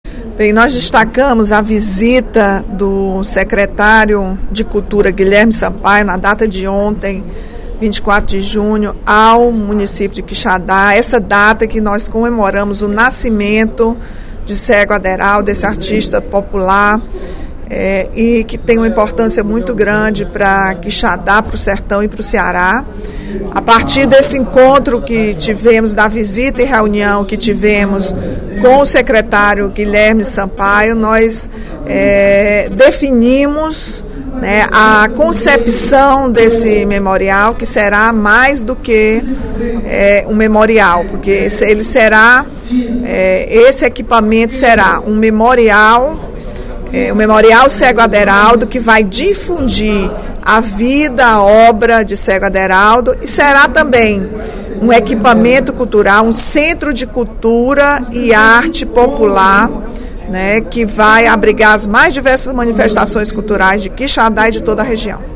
A deputada Rachel Marques (PT) comentou, durante o primeiro expediente da sessão plenária desta quinta-feira (25/06), o incêndio de grandes proporções que atingiu duas lojas na região central do município de Quixadá, no domingo (21/06). A parlamentar defendeu a instalação de uma unidade do Corpo de Bombeiros na cidade.